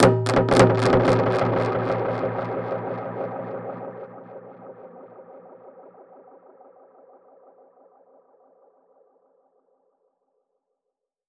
Index of /musicradar/dub-percussion-samples/85bpm
DPFX_PercHit_C_85-08.wav